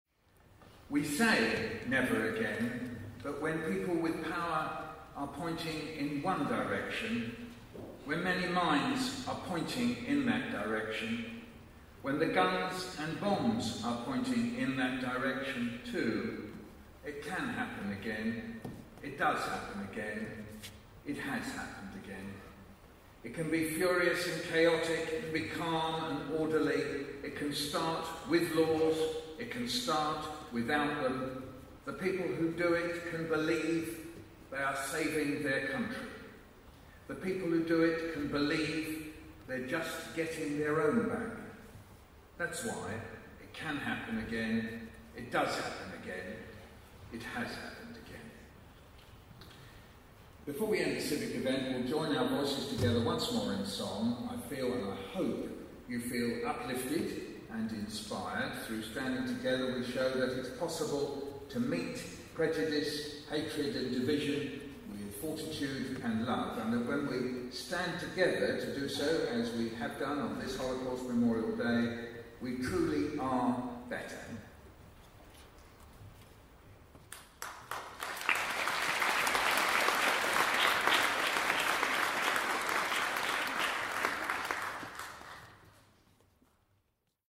Never Again read by Michael Rosen